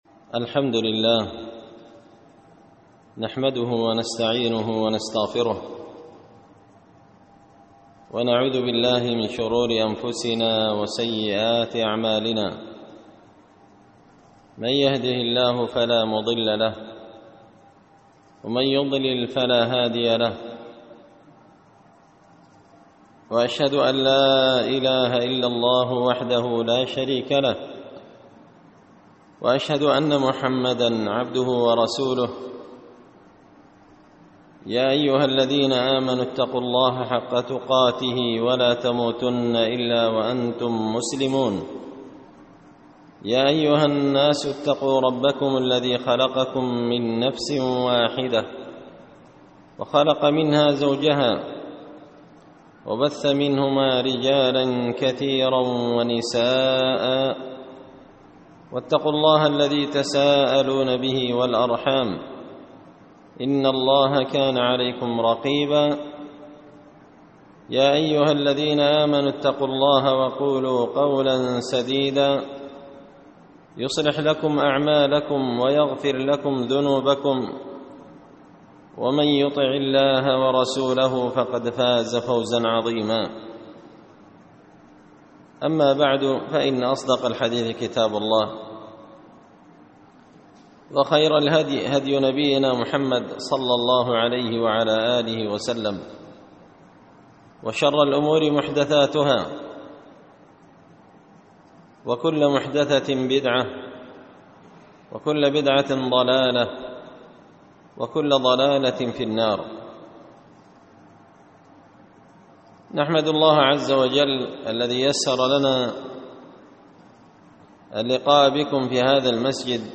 خطبة جمعة بعنوان – الصلاة وأهمينها في دين الإسلام
دار الحديث بمسجد الفرقان ـ قشن ـ المهرة ـ اليمن